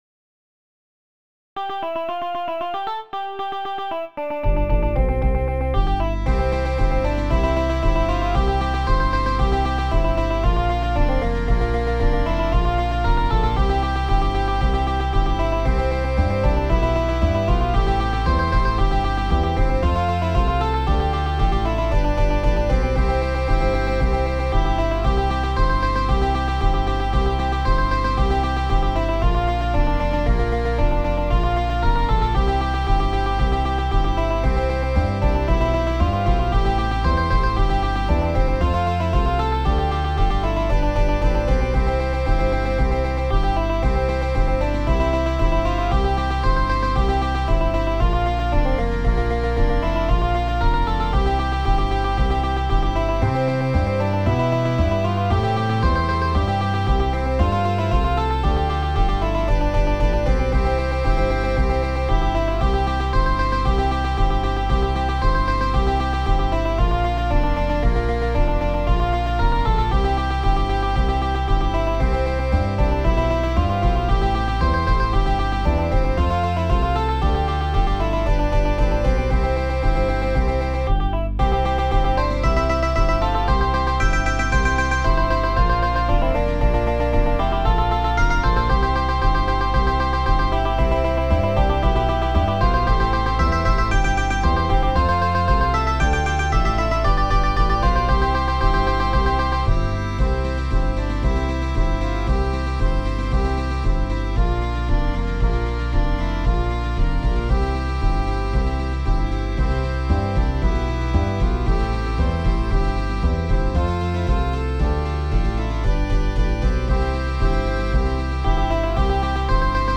gallwahls.mid.ogg